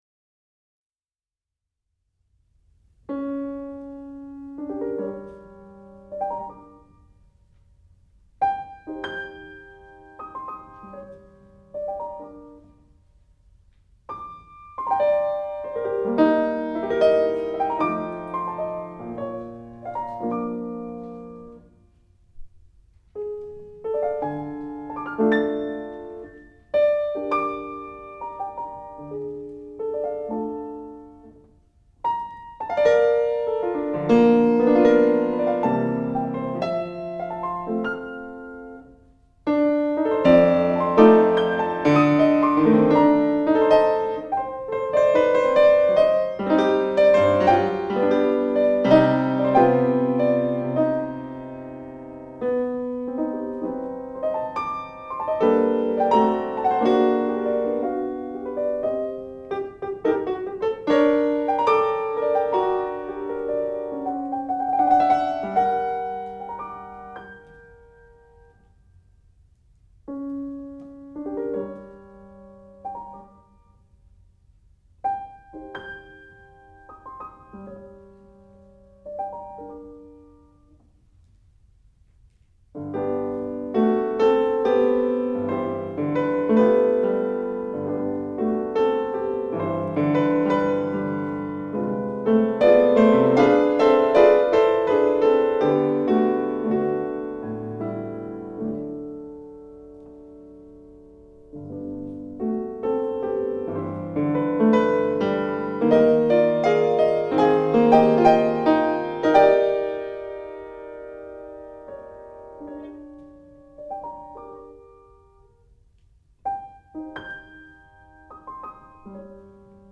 過去の演奏のご紹介
Piano